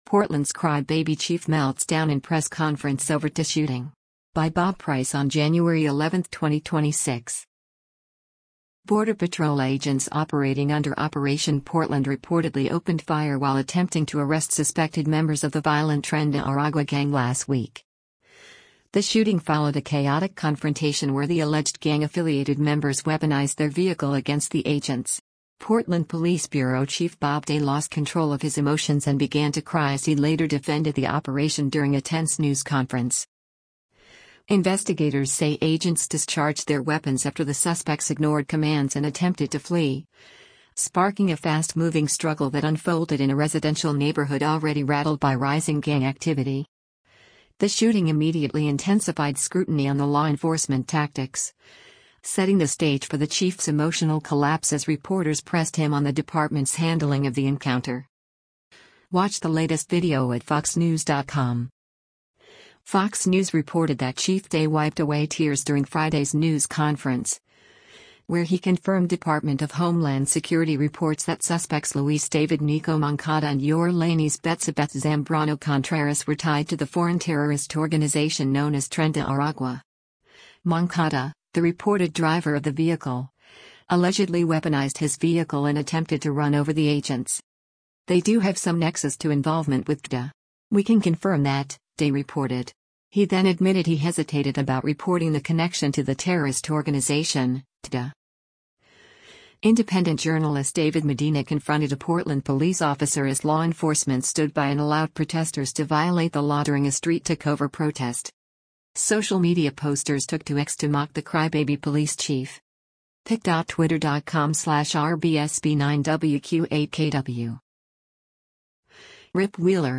Portland’s ‘Cry Baby’ Chief Melts Down in Press Conference over TdA Shooting
Portland Police Bureau Chief Bob Day lost control of his emotions and began to cry as he later defended the operation during a tense news conference.